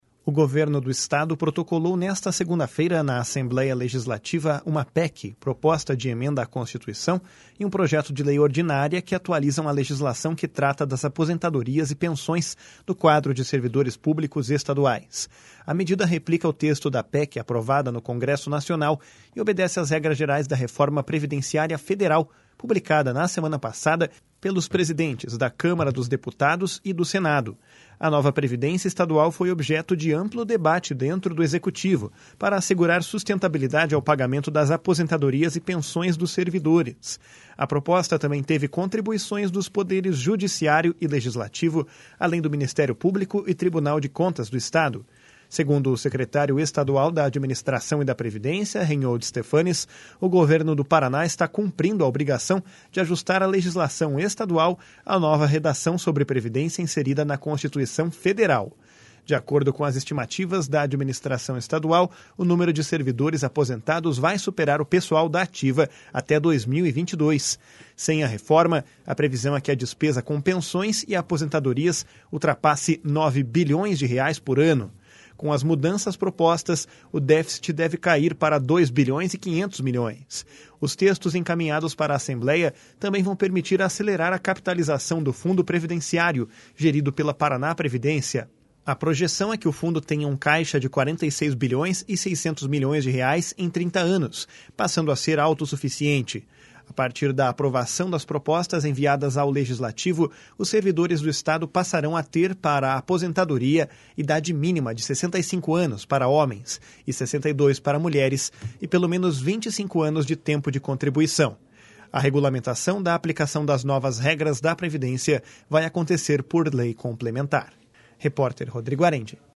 Segundo o secretário estadual da Administração e da Previdência, Reinhold Stephanes, o Governo do Paraná está cumprindo a obrigação de ajustar a legislação estadual à nova redação sobre previdência inserida na Constituição Federal.